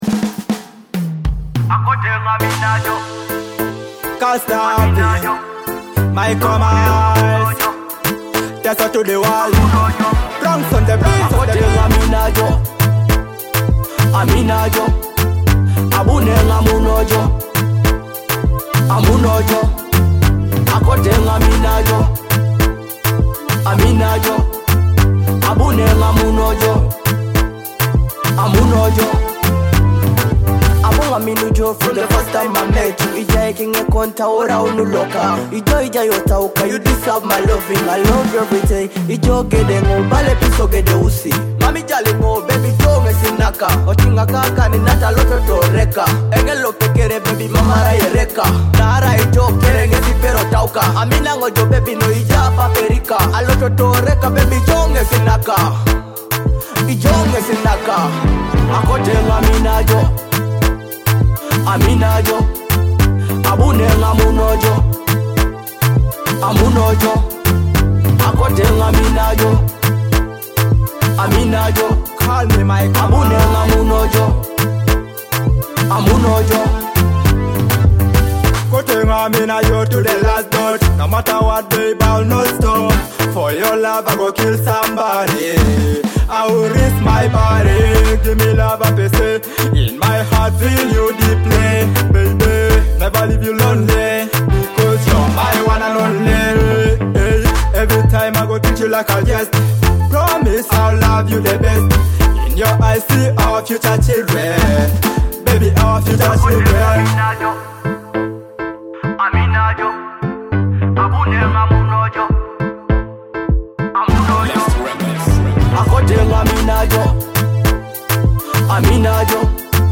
heartfelt Ugandan love song
fresh Afrobeat vibes